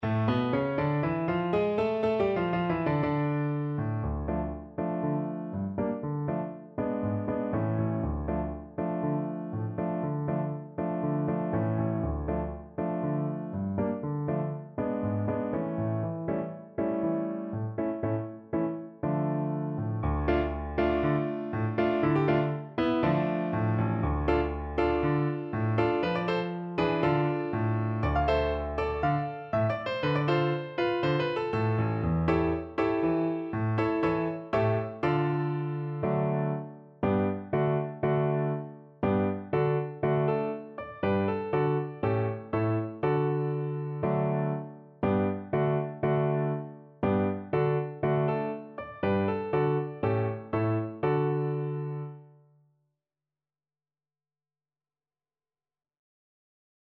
Play (or use space bar on your keyboard) Pause Music Playalong - Piano Accompaniment Playalong Band Accompaniment not yet available reset tempo print settings full screen
2/4 (View more 2/4 Music)
A minor (Sounding Pitch) (View more A minor Music for Alto Recorder )
Allegro (View more music marked Allegro)